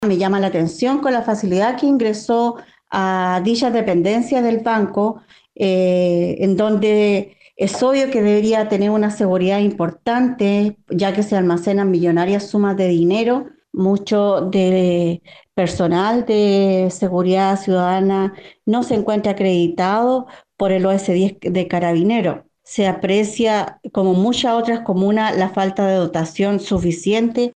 En tanto, la concejala de La Ligua, Ligia Osorio, cuestionó la fácil vulneración que sufrió esta sucursal bancaria y realizó algunas observaciones a la seguridad de la comuna.
cu-robo-banco-concejala.mp3